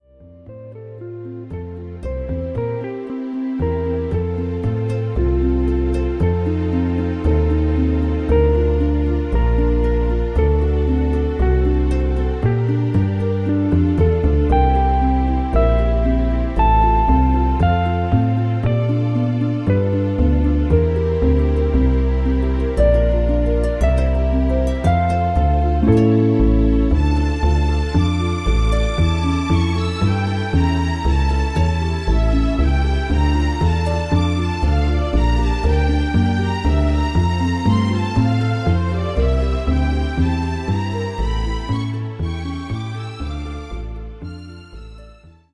version lente